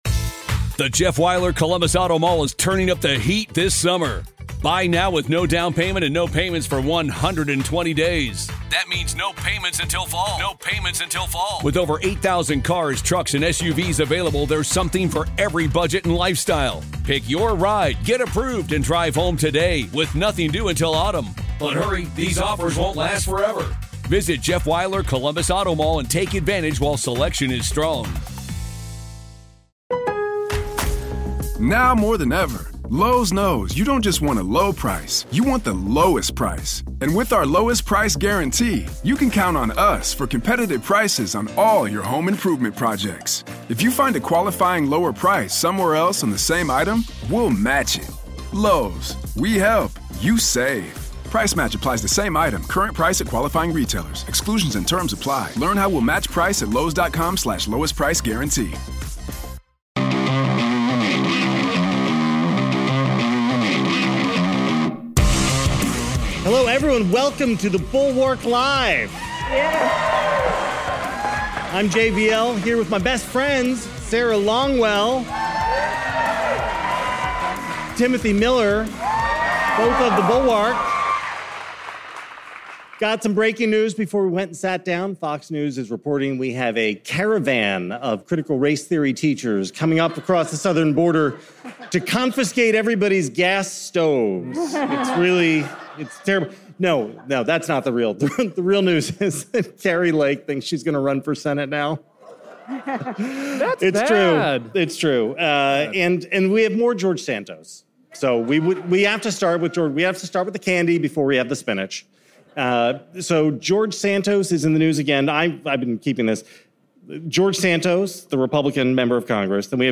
The gang hit the road this week with a live show from the Avalon Hollywood.